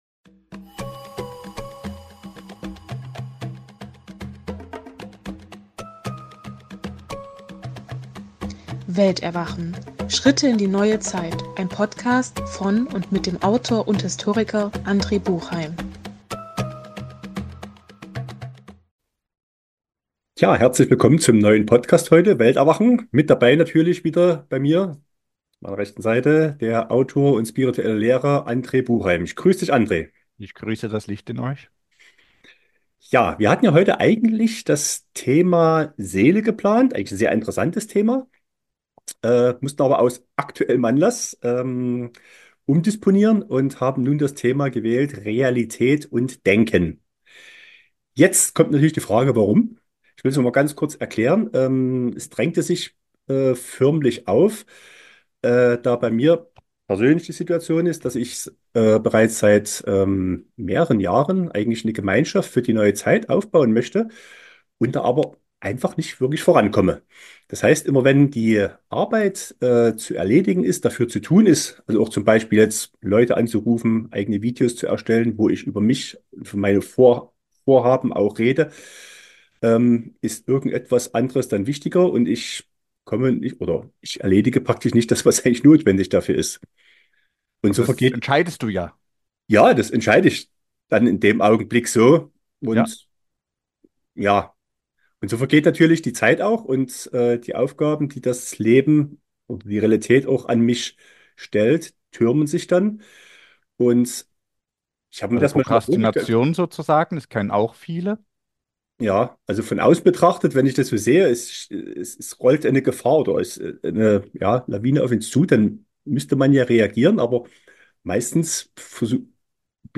In einem lockeren Gespräch wird das Thema "Realität & Denken" behandelt und wichtige Aspekte / Ansichten dazu erläutert, sowie sehr hilfreiche Zitate von Philosophen eingespielt.